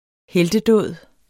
Udtale [ ˈhεldəˈdɔˀð ]